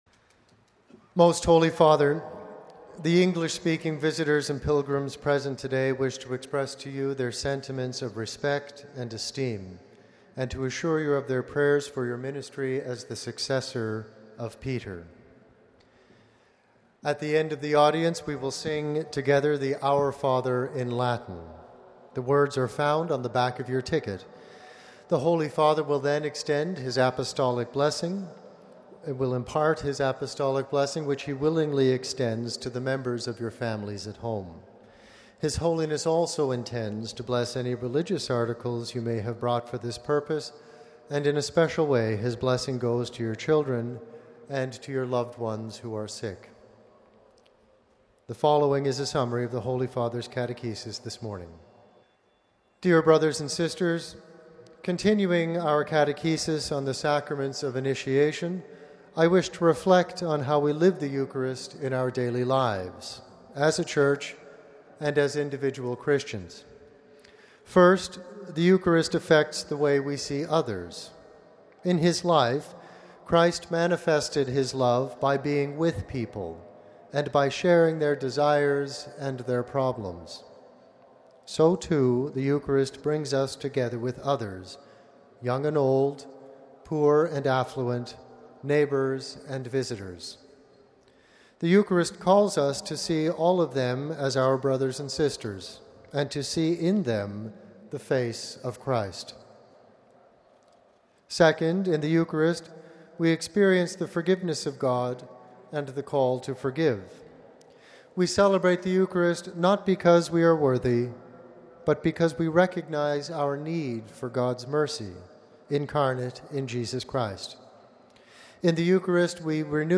The general audience of February 12 was held in the open, in Rome’s St. Peter’s Square.
Basing himself on the scripture passage, Pope Francis delivered his main discourse in Italian - summaries of which were read out by aides in various languages, including in English. But first, the aide greeted the Pope on behalf of the English-speaking pilgrims.